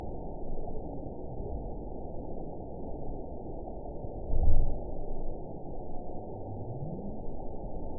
event 922044 date 12/26/24 time 00:17:17 GMT (5 months, 3 weeks ago) score 9.53 location TSS-AB03 detected by nrw target species NRW annotations +NRW Spectrogram: Frequency (kHz) vs. Time (s) audio not available .wav